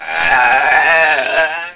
Sheep1
SHEEP1.WAV